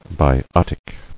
[bi·ot·ic]